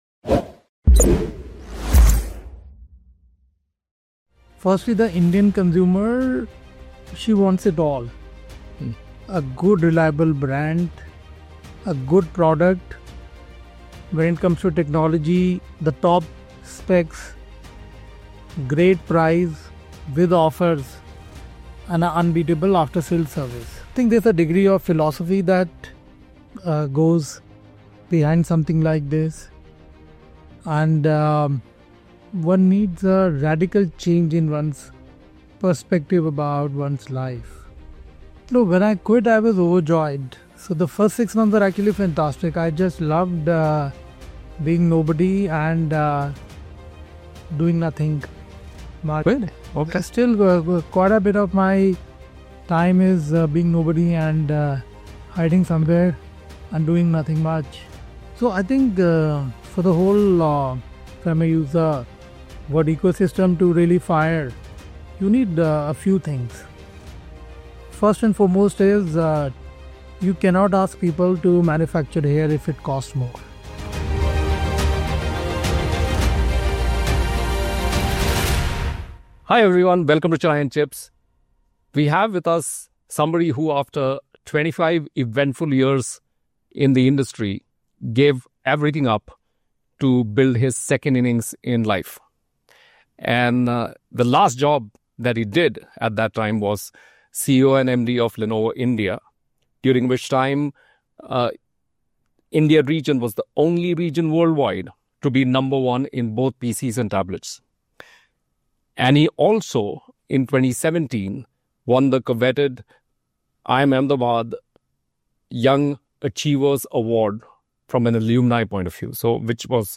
Tune in for a candid conversation on: The Mindset of the Indian Consumer: Why they "want it all" and what it takes to win in this unique market. Real-World Leadership: The tough decisions that shaped his journey, including the jump from marketing to running a half-billion-dollar P&L. Make in India: A pragmatic look at the challenges and opportunities of building a manufacturing ecosystem in India.